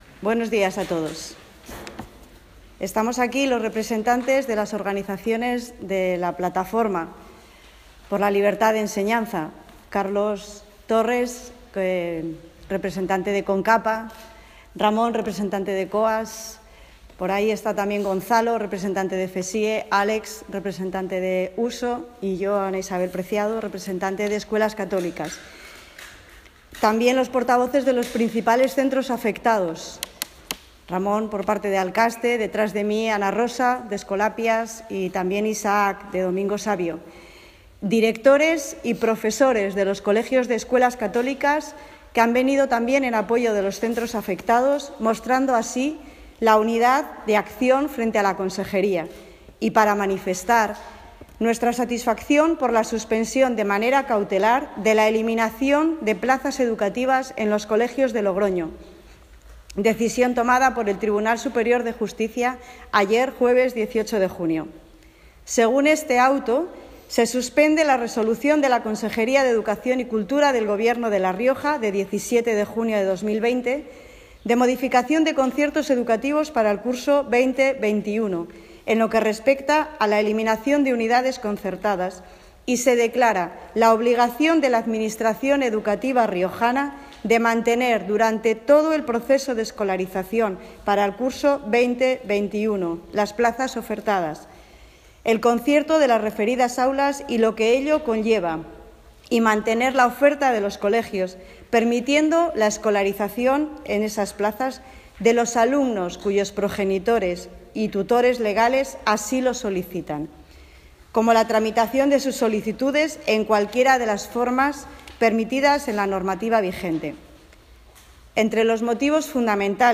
Audios con las intevenciones en la rueda de prensa y por orden de participación: